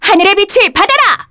Artemia-Vox_Skill1_kr.wav